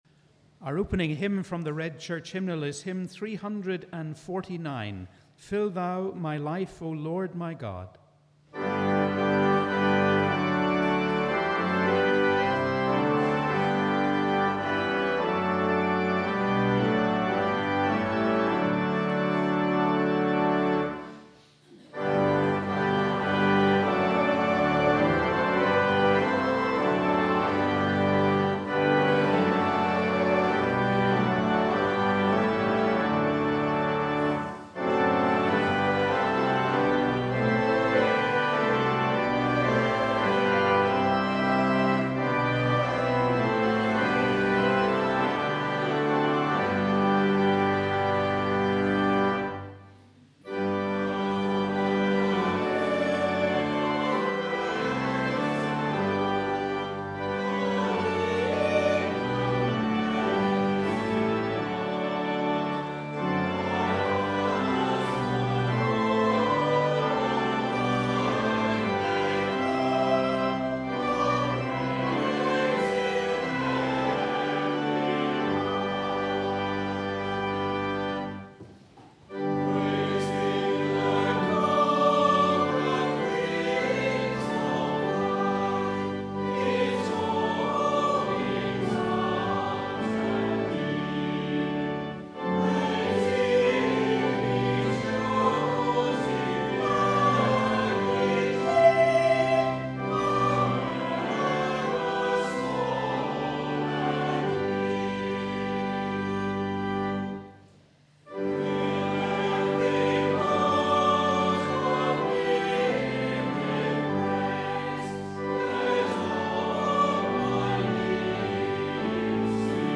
We warmly welcome you to our service of Morning Prayer on the 18th Sunday after Trinity.